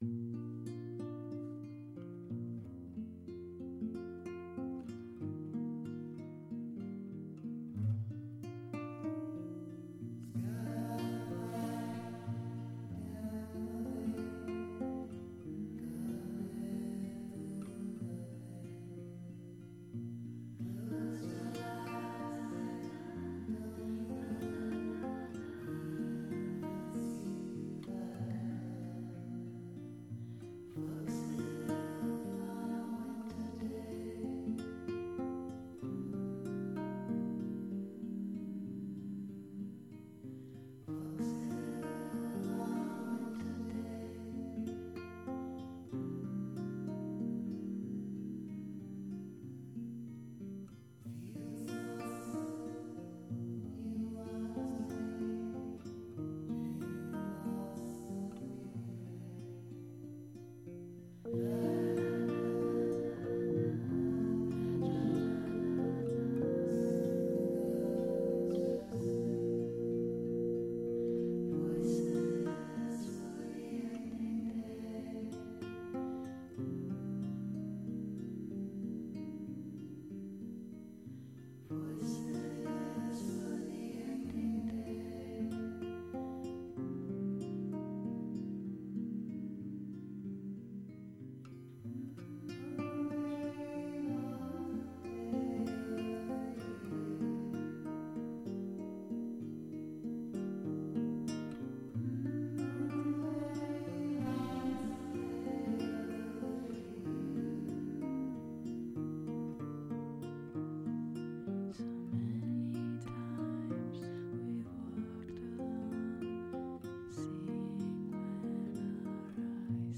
tra concerti acustici e piccoli festival indiepop
rarefatta